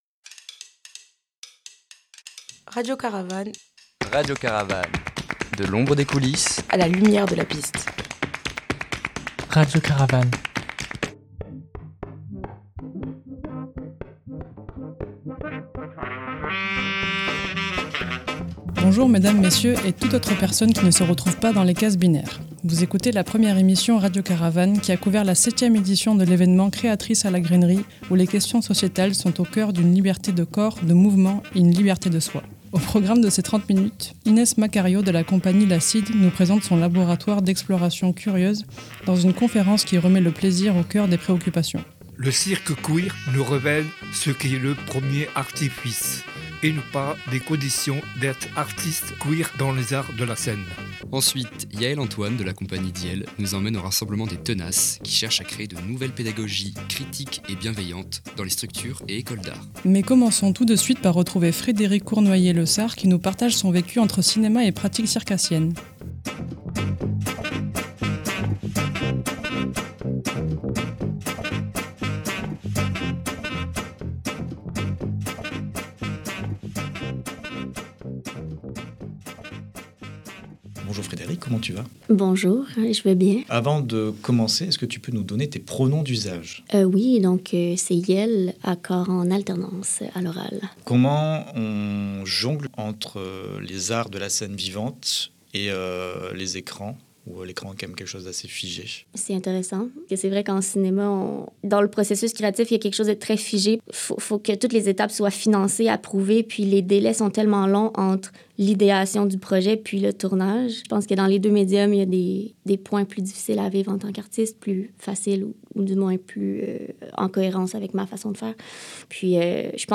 Nous avons couvert la septième édition de l’événement « Créatrices » à la Grainerie, qui a mis à l’honneur les créations autour des libertés de genres, de corps, de consentir ou pas…